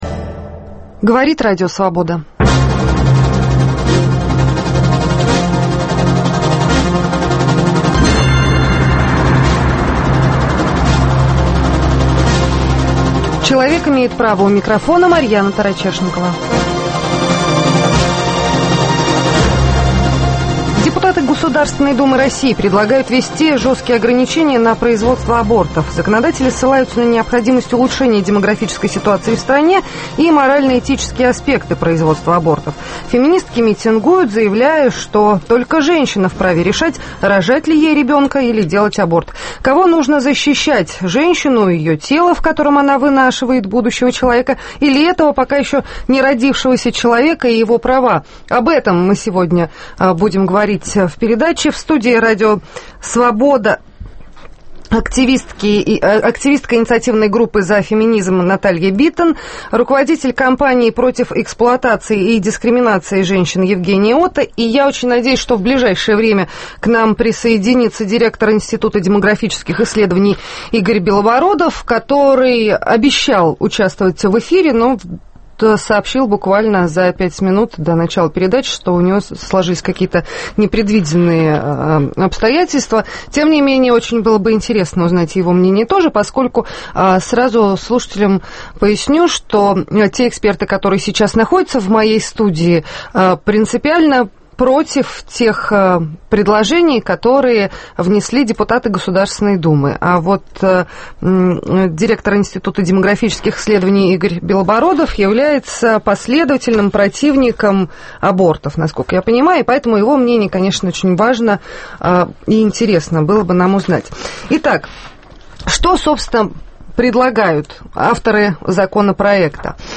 В студии РС Директор